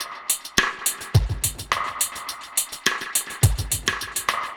Index of /musicradar/dub-drums-samples/105bpm
Db_DrumKitC_EchoKit_105-03.wav